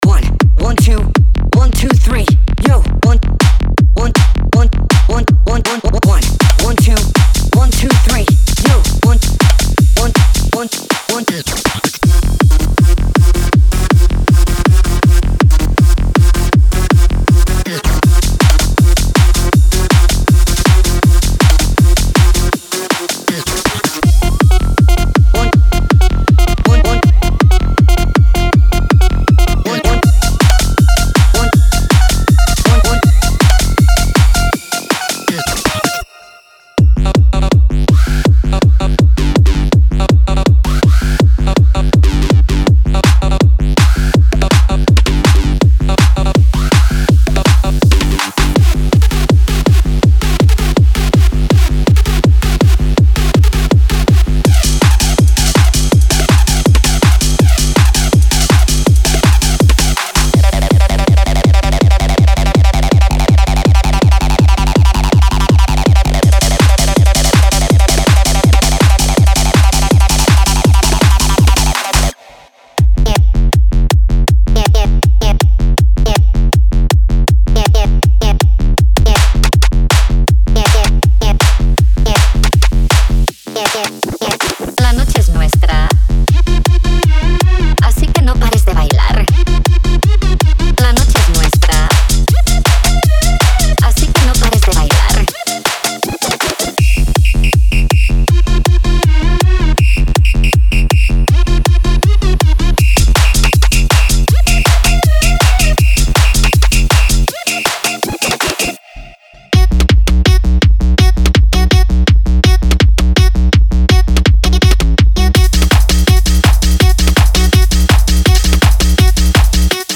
Genre:Hard Dance
タフで軍隊的なドラムは駆動感のある4/4グリッドにしっかりロックされ、ベースラインはじっとしていません。
速く、パンチがあり、遊び心に富み、満員のフロアや深夜のセットに最適です。
デモサウンドはコチラ↓